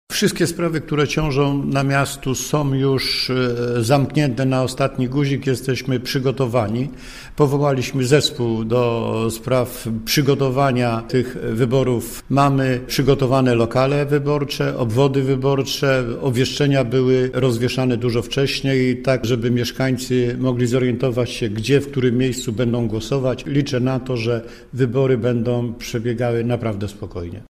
Organizacyjnie wszystko zostało przygotowane – mówi Maciej Jankowski pełniący obowiązki prezydenta Nowej Soli.